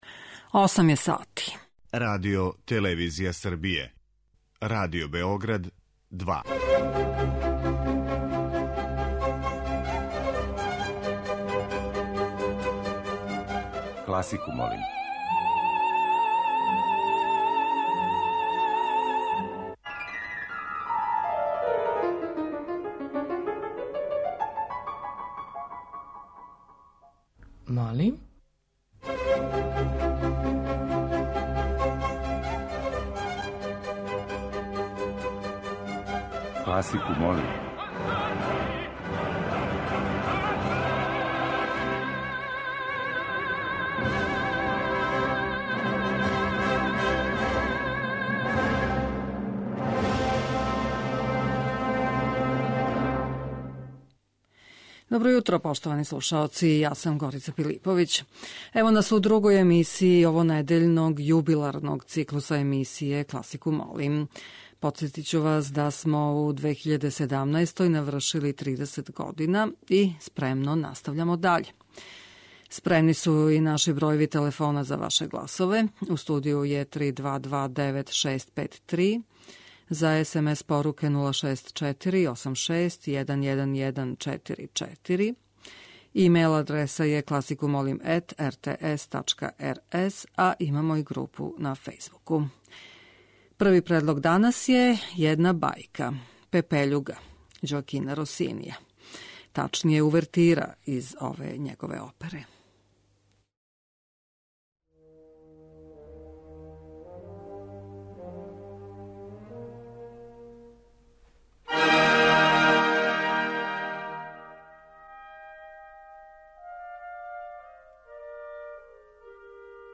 некадашњи и садашњи водитељи, те неколико слушалаца - говоре о свом односу према емисији и препоручују свој музички предлог за избор топ-листе у петак.